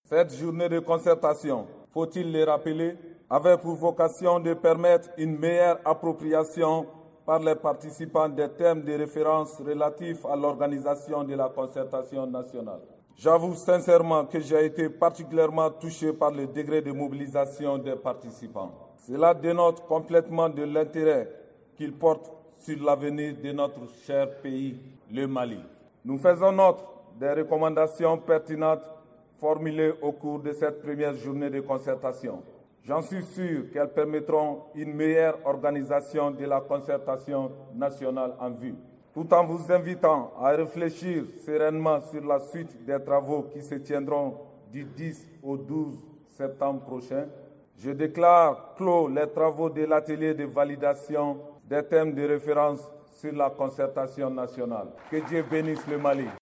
REACTION-VICE-PRESIDENT-CNSP.mp3